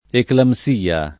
eclamsia [ eklam S& a ]